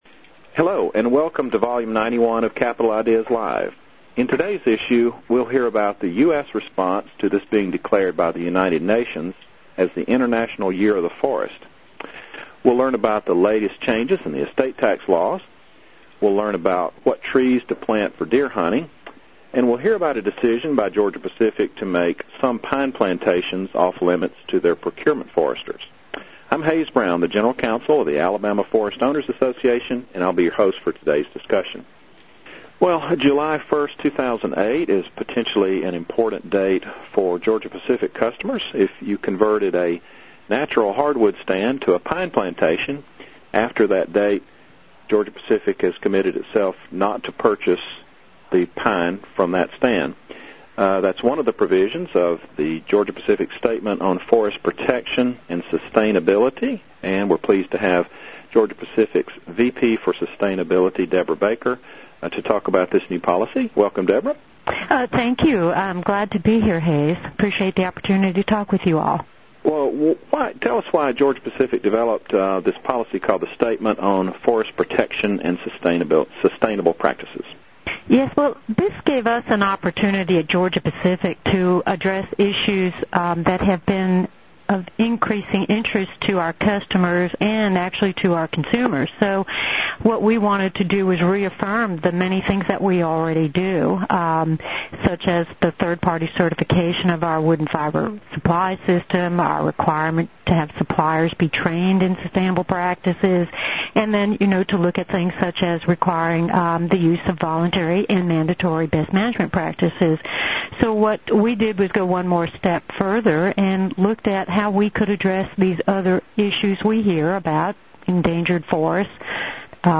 JANUARY 2011 News Conference for Forest Owners Sponsored by the Alabama Forest Owners' Association, Inc. This Conference was recorded on January 19, 2011.